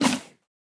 哞菇：进食
玩家喂食棕色哞菇时随机播放这些音效
Minecraft_mooshroom_eat1.mp3